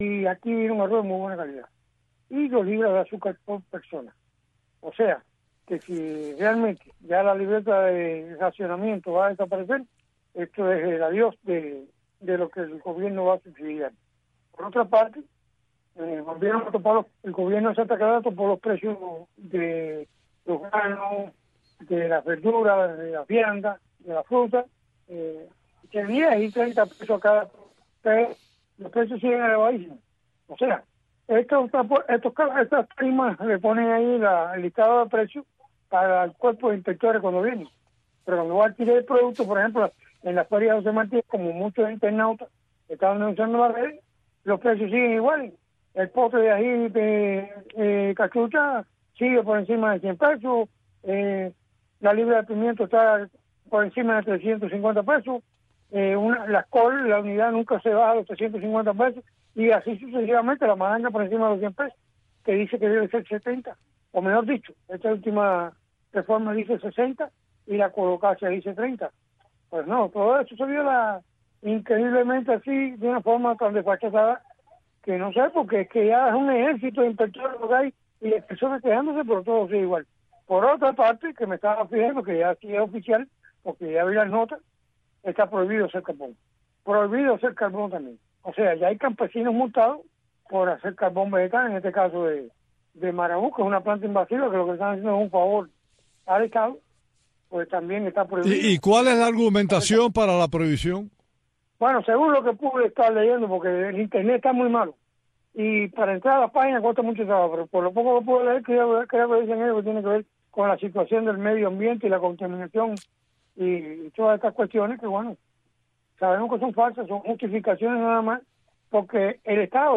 conecta cada día con sus invitados en la isla en este espacio informativo en vivo, que marca el paso al acontecer cubano.